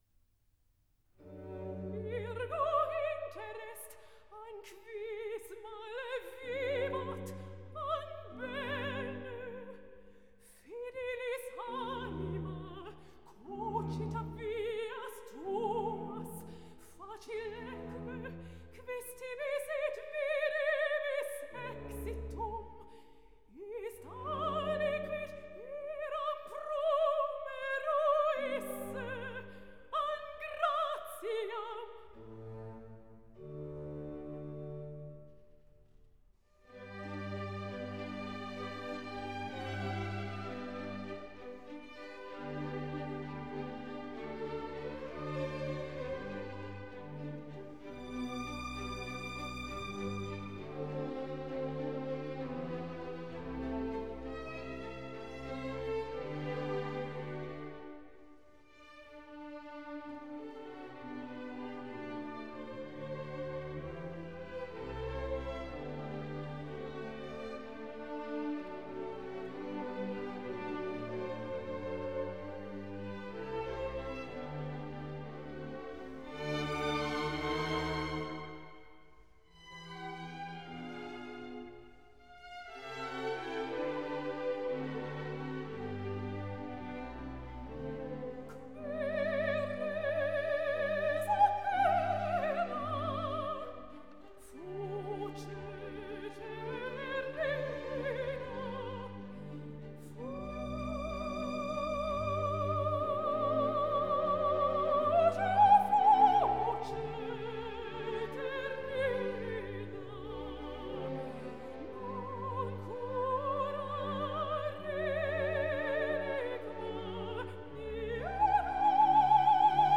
in G